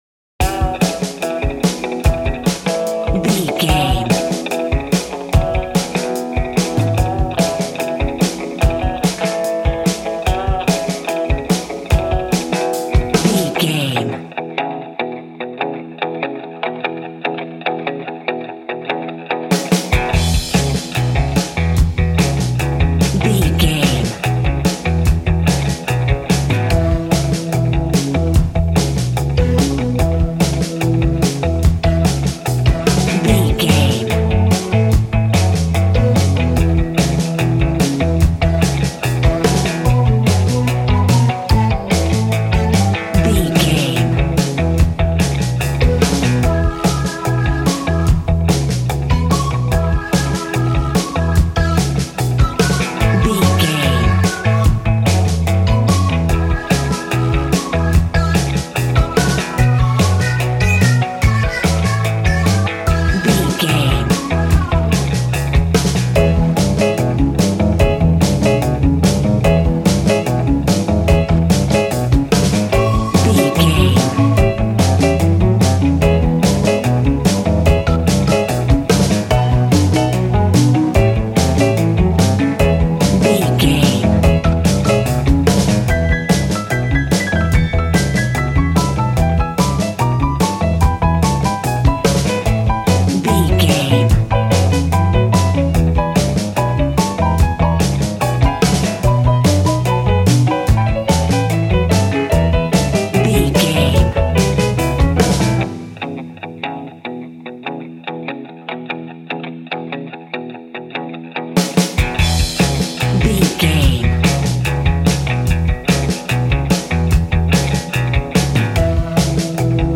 royalty free music
Ionian/Major
cheerful/happy
cool
double bass
piano
drums